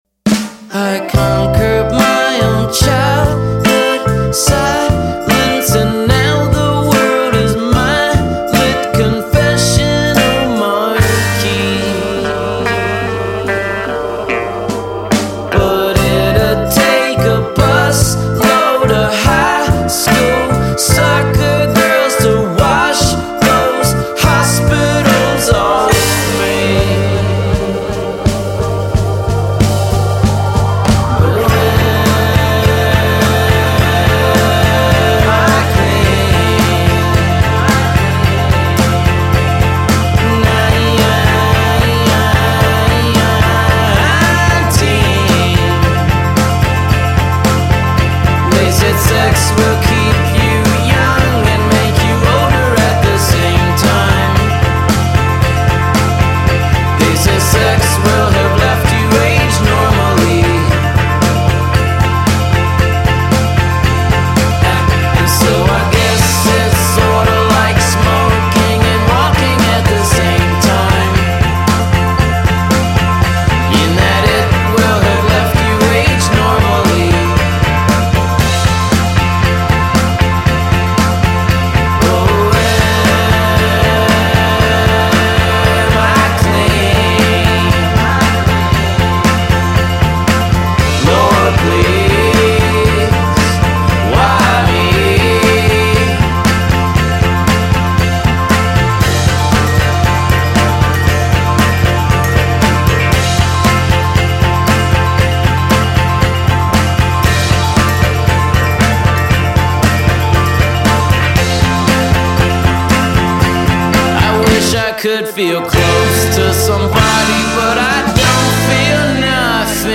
הוא תמיד עצוב ואכול בדידות